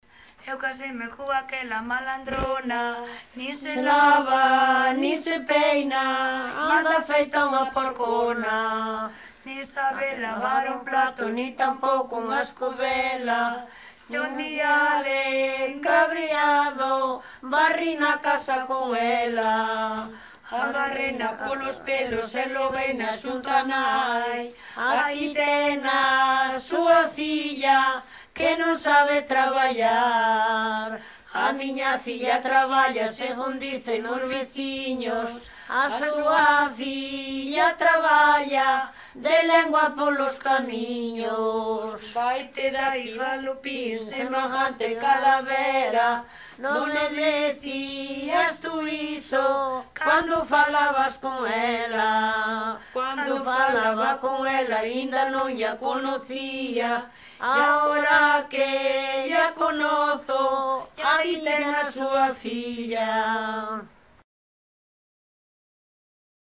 Canto estrófico
Tipo de rexistro: Musical
Áreas de coñecemento: LITERATURA E DITOS POPULARES > Cantos narrativos
Soporte orixinal: Casete
Instrumentación: Voz
Instrumentos: Voces femininas